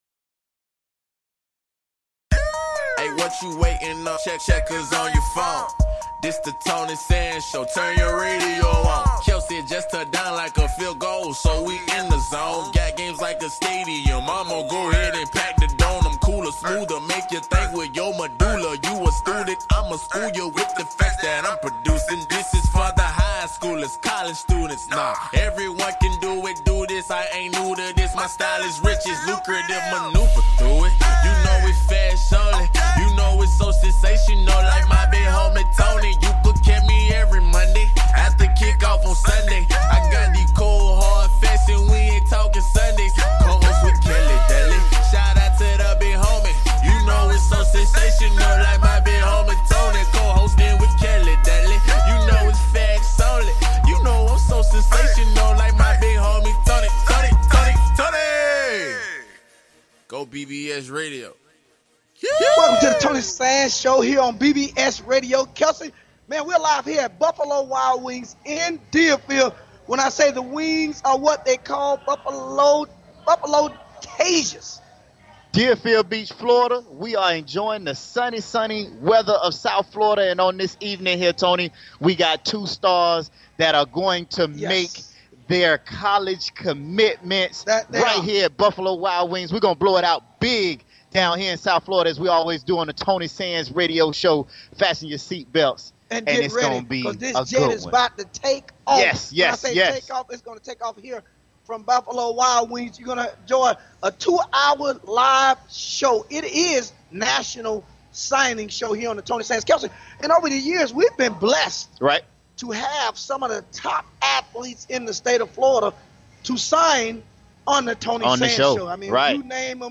A packed house here in Deerfield Beach
Talk Show